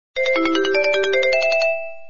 分类: 短信铃声